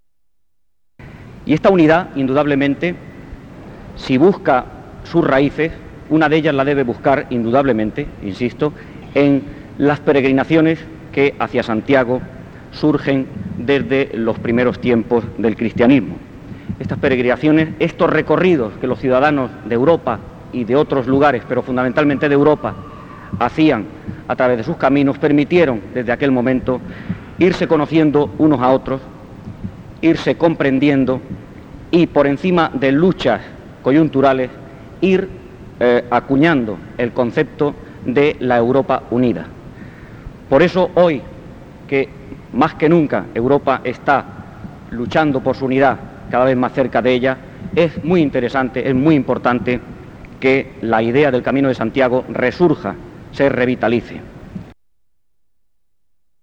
Bienvenida del Ilmo. Sr. D. Juan Ramón Pajares Gutiérrez, Director del Gabinete del Ministerio de Obras Públicas y Urbanismo y Presidente de la Comisión Interministerial para la Revitalización del Camino de Santiago
I Congreso Internacional de Jaca. Saludo de las Autoridades. Miércoles 23 de septiembre, S.I. Catedral de Jaca, 1987